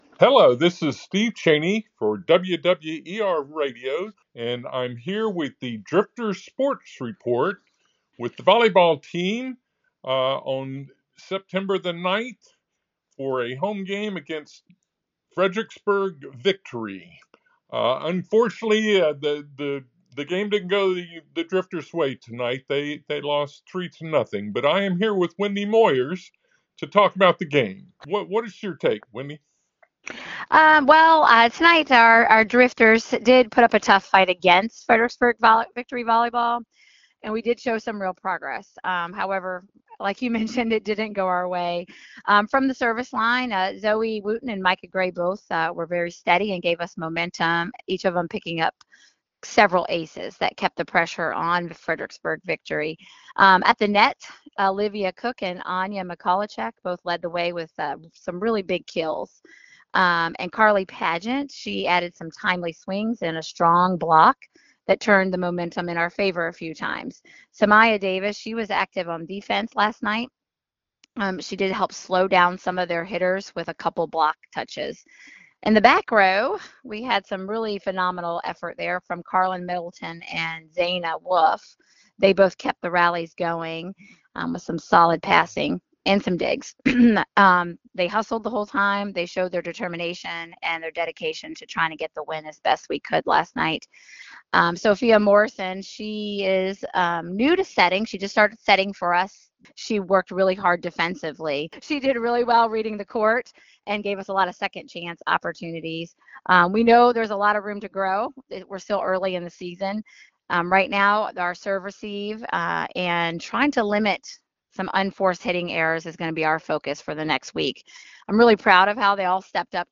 Drifters Sports Report